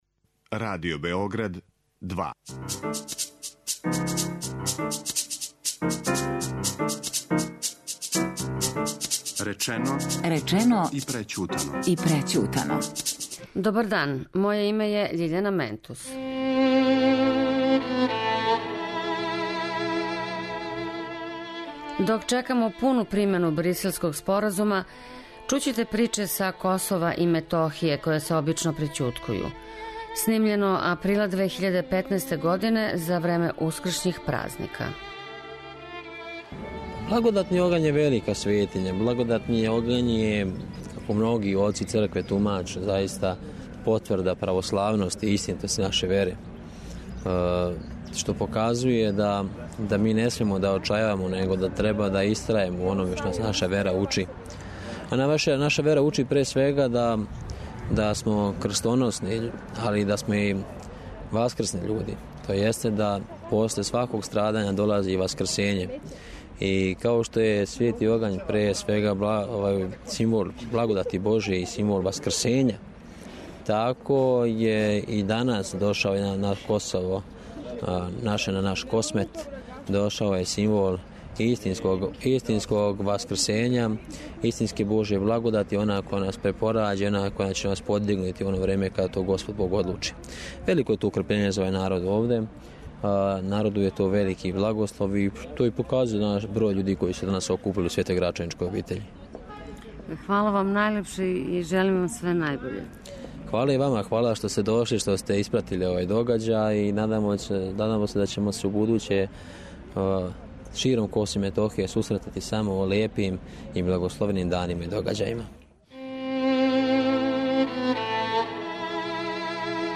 У емисији говоре људи из енклава или гета на Косову и Метохији.